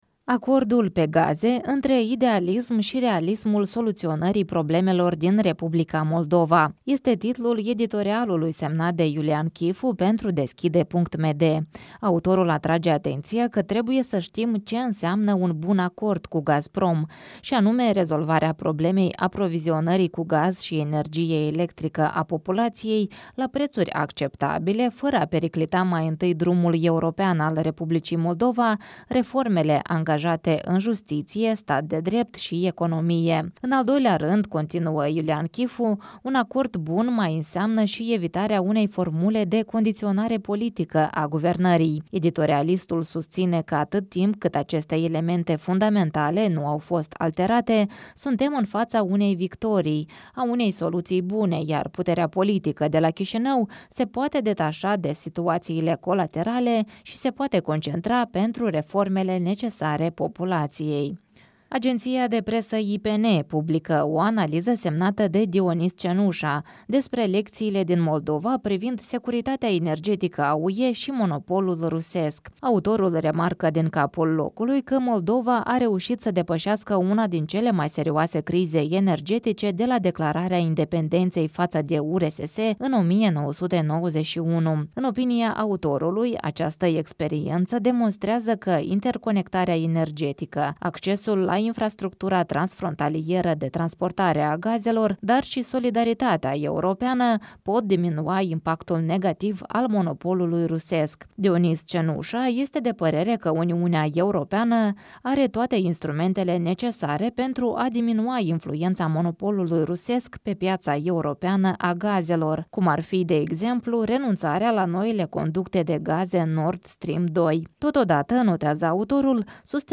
Revista matinală a presei de la Chișinău.